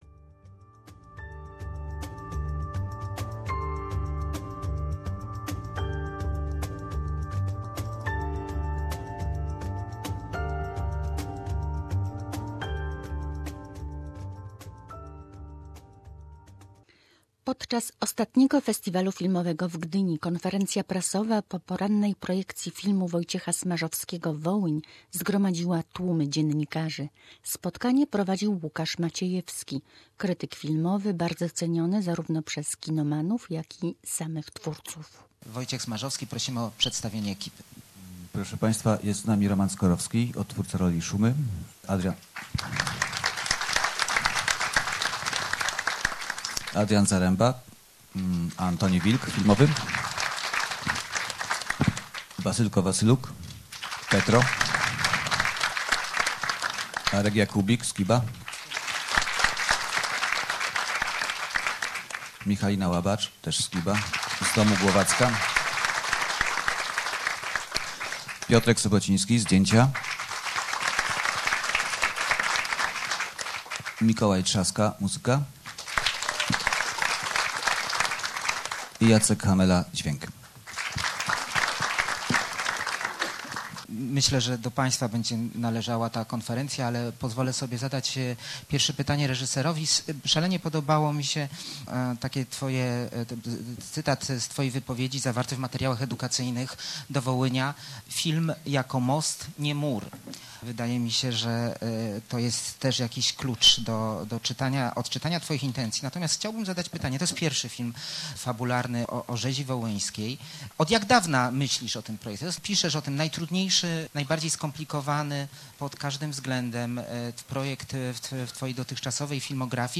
Fragmenty konferencji prasowej z Festiwalu Filmowego w Gdyni.